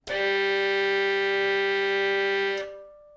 That distance was adjusted to have, qualitatively, a strong direct sound with a bit of reverberation, in a quiet laboratory room.
Bowing machine. Audio file Violin5_BowingMachine_G